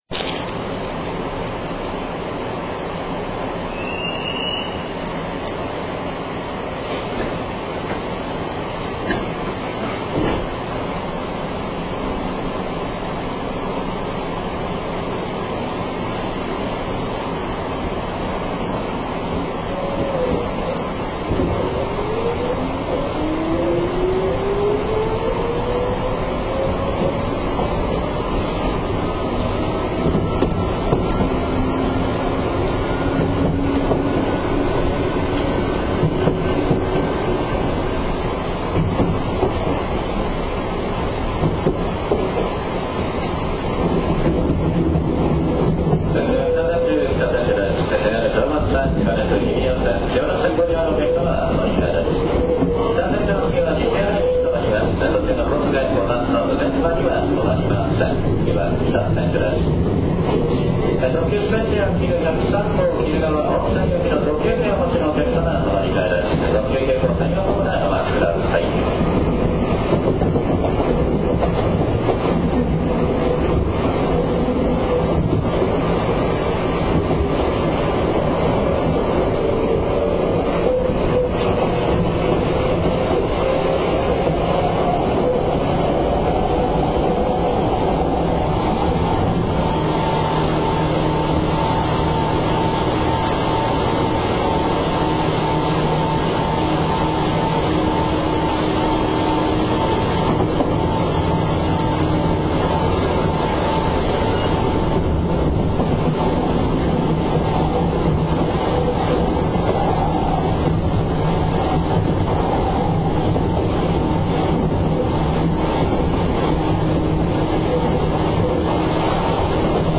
Motor Sound